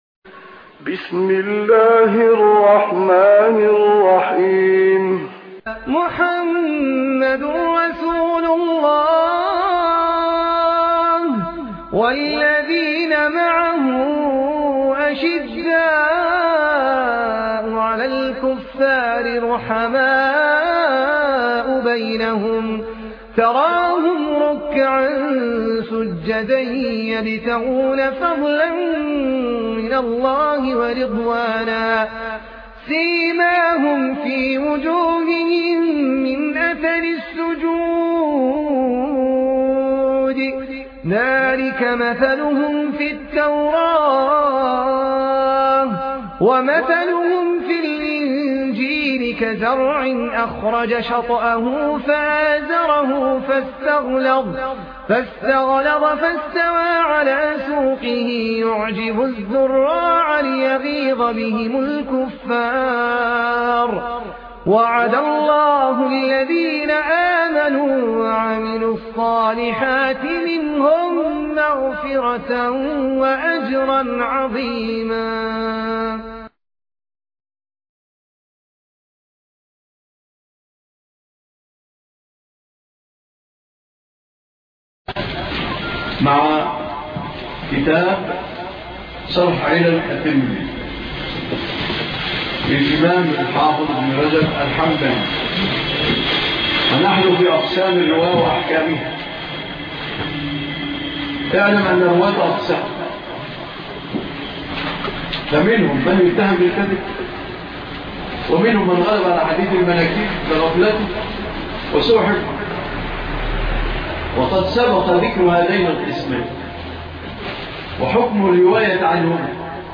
الدرس 17 ( شرح علل ابن رجب )